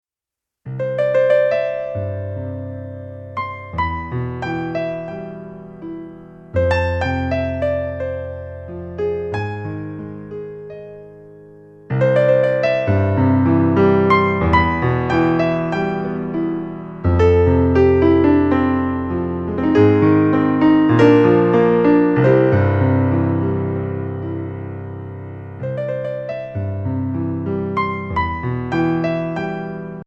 Instrumental Album Download